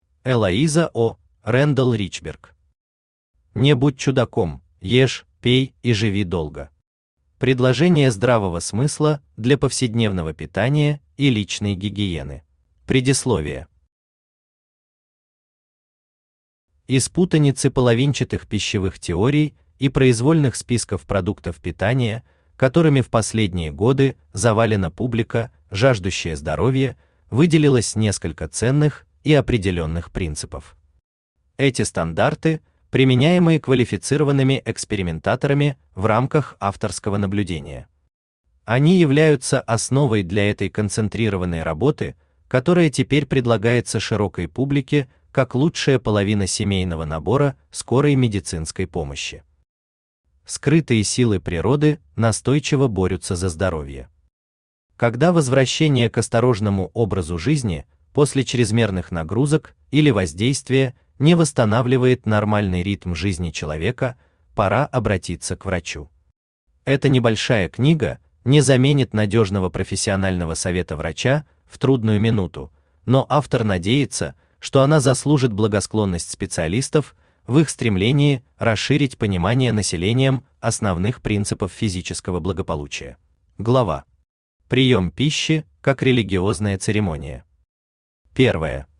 Аудиокнига Не будь чудаком, ешь, пей и живи долго.
Предложения здравого смысла для повседневного питания и личной гигиены Автор Элоиза О. Рэндалл Ричберг Читает аудиокнигу Авточтец ЛитРес.